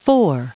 Prononciation du mot for en anglais (fichier audio)
Prononciation du mot : for